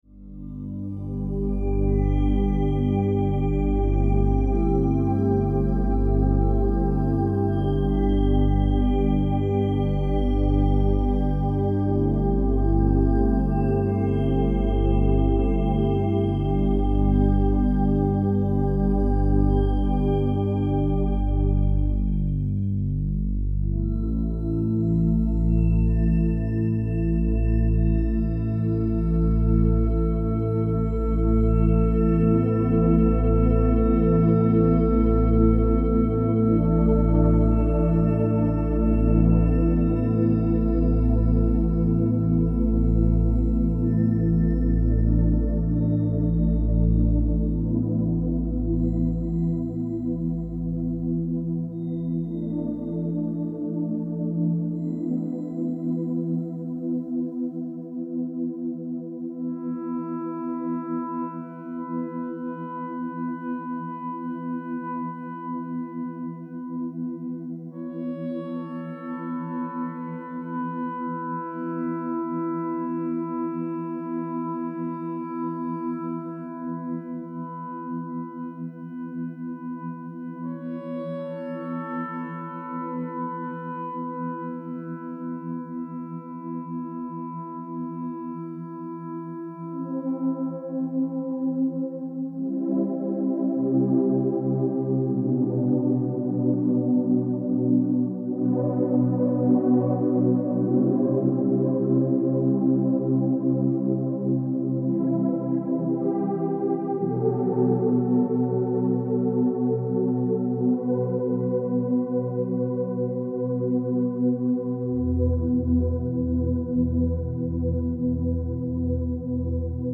transformational healing music